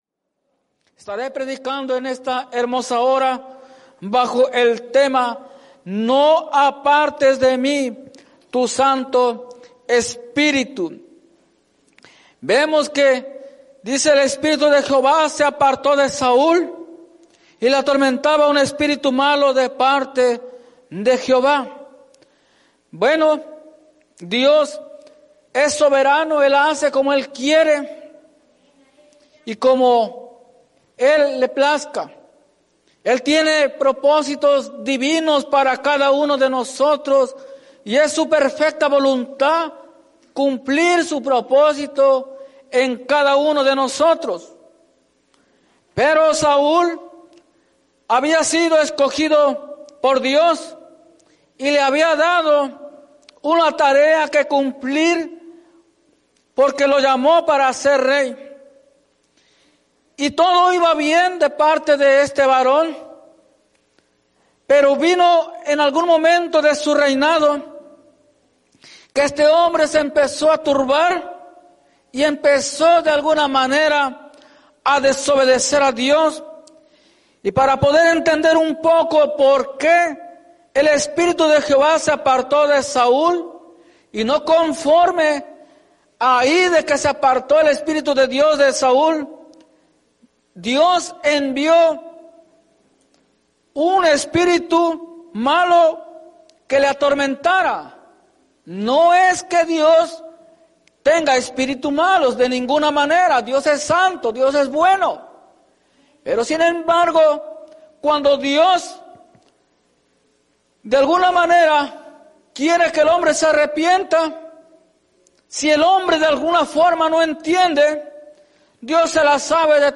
Iglesia Misión Evangélica Tema: No apartes de mí Tu Santo Espíritu Predica